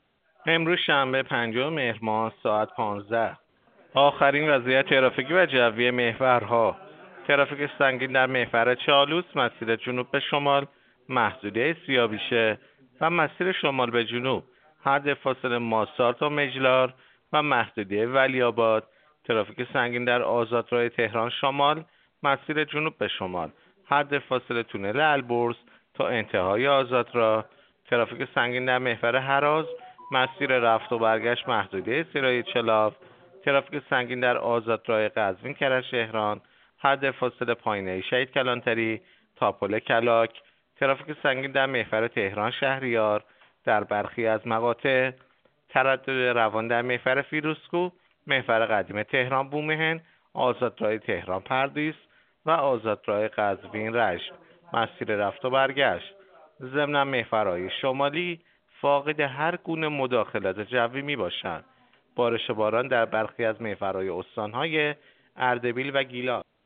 گزارش رادیو اینترنتی از آخرین وضعیت ترافیکی جاده‌ها ساعت ۱۵ پنجم مهر؛